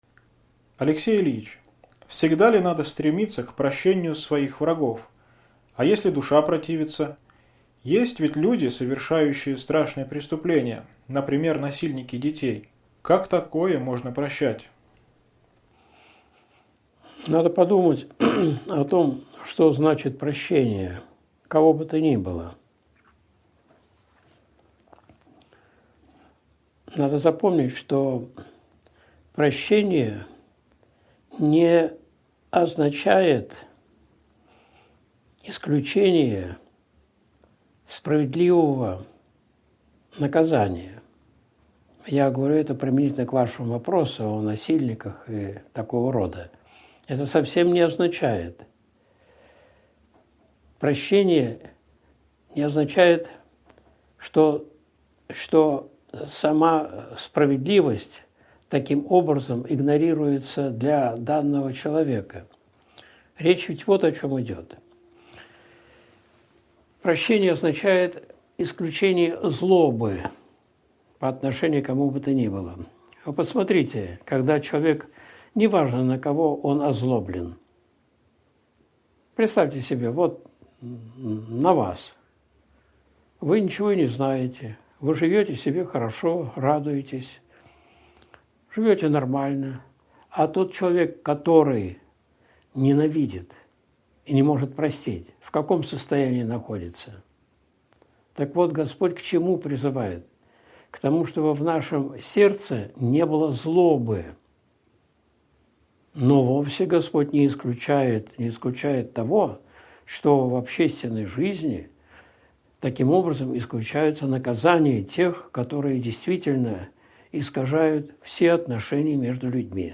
Ответы на вопросы (Часть 1) (Прямой эфир, 11.12.2020)
Видеолекции протоиерея Алексея Осипова